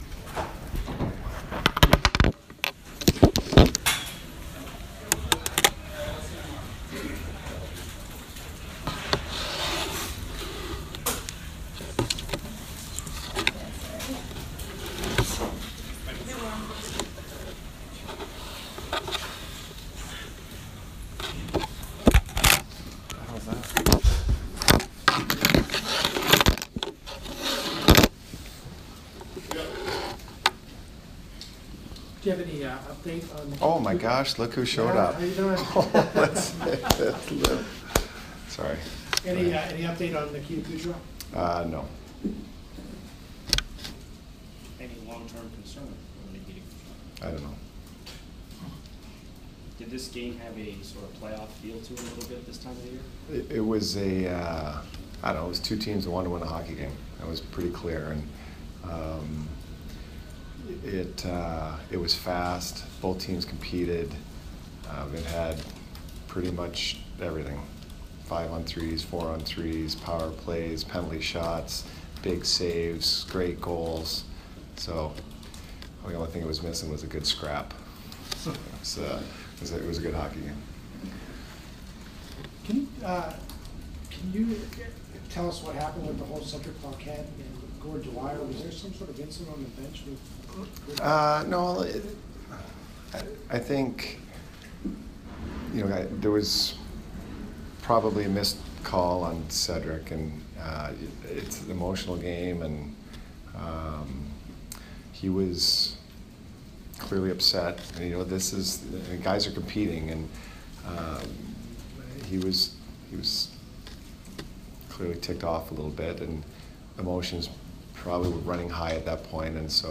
Jon Cooper post-game 2/26